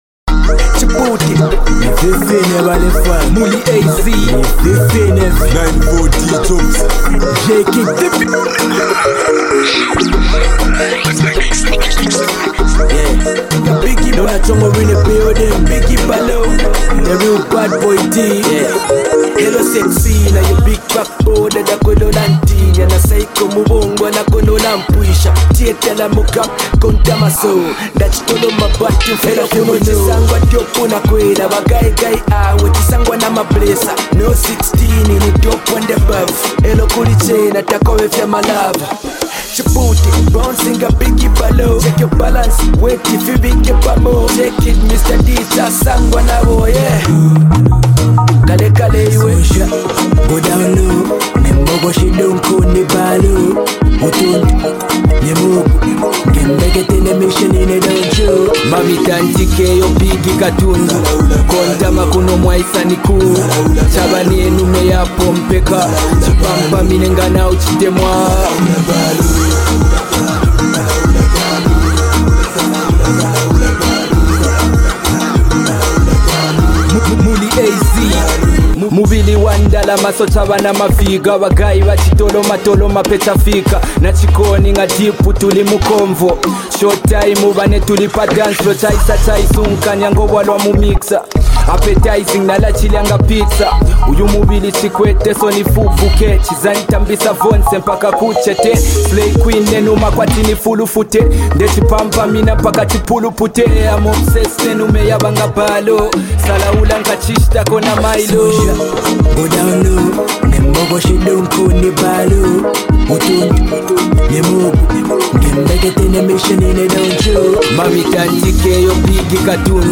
dance-hall joint